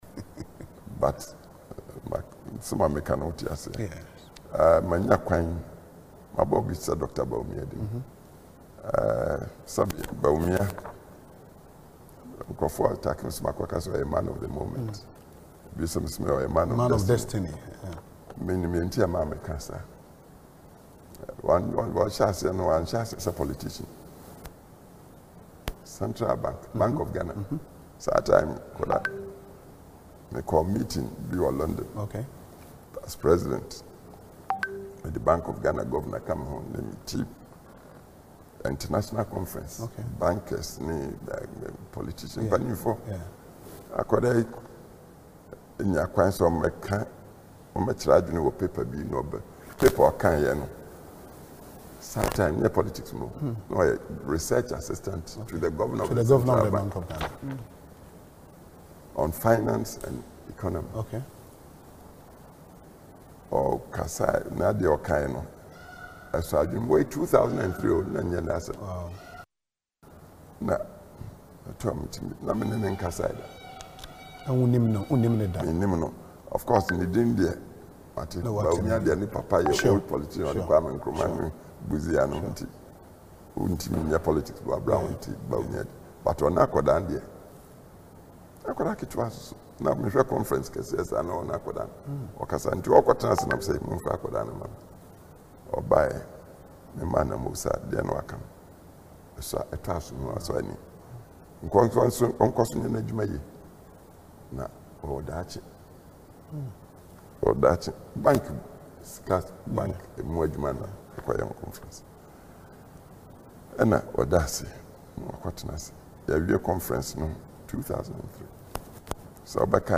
Speaking in an interview on Asempa FM’s Ekosii Sen show, he described him as “a man of destiny.”